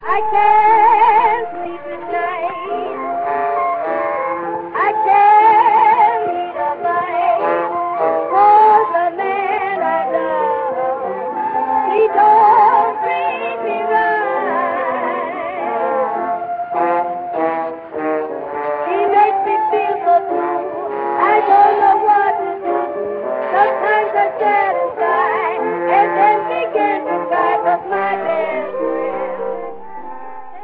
сделавших сольную блюзовую запись.